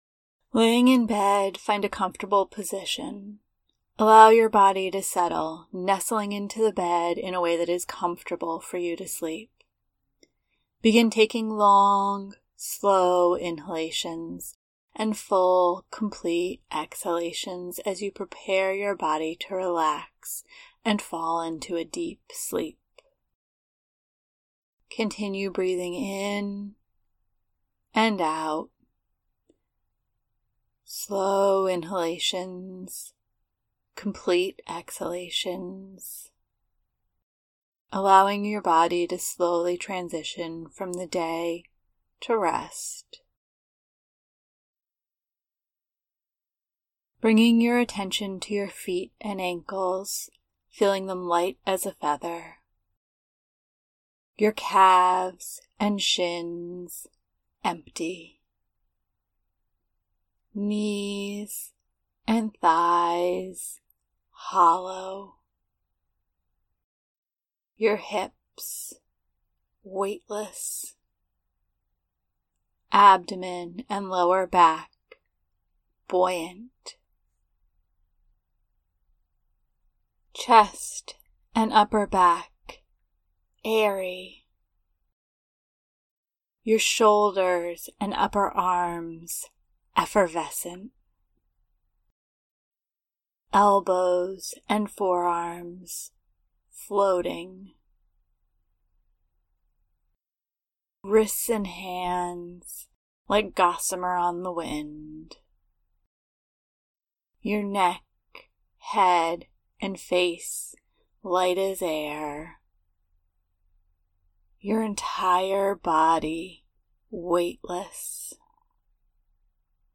Light to Heavy Body Scan meditation
There’s nothing at the end of this recording to indicate it’s complete as I hope that you will have fallen asleep before it’s finished and I don’t want to pull you out of your nascent slumber.
Body-Scan-Light-to-Heavy-Updated.mp3